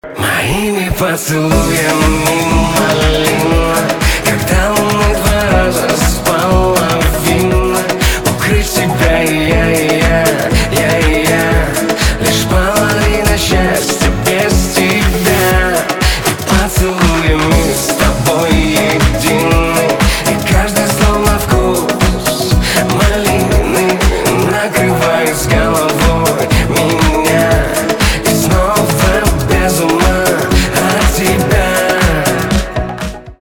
поп
битовые , кайфовые , чувственные , романтические